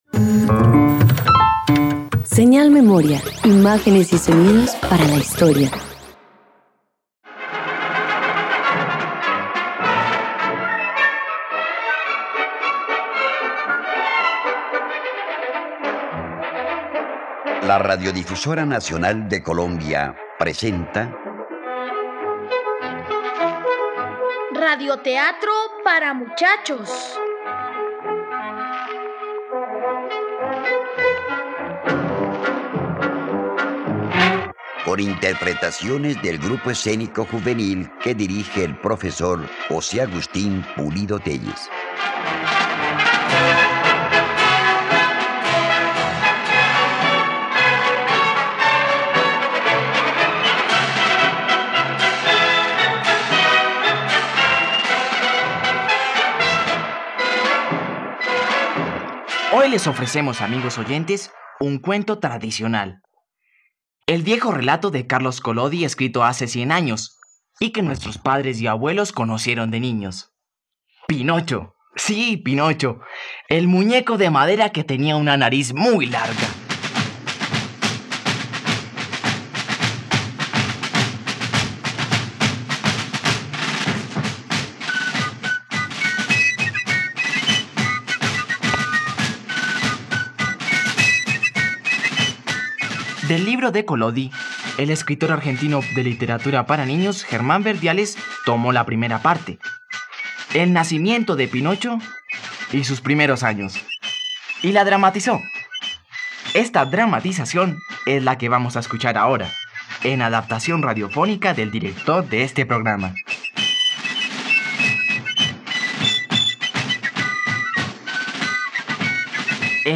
..Radioteatro. Escucha la adaptación de la obra "Pinocho" del escritor italiano Carlo Collodi, disponible en la plataforma de streaming RTVCPlay.